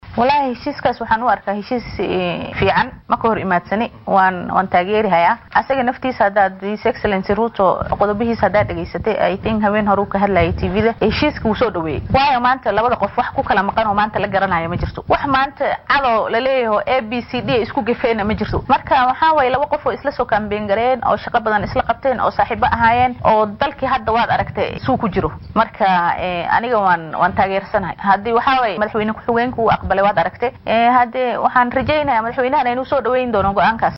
Wakiilka haweenka ee ismaamulka Garissa Canab Suuboow oo wareysi gaar ah siisay warbaahinta Star ayaa gaashaanka u daruurtay dhaqdhaqaaqa cusub ee UPYA ee dhawaan ay shaaciyeen qaar ka mid ah madaxda xoolo dhaqatada.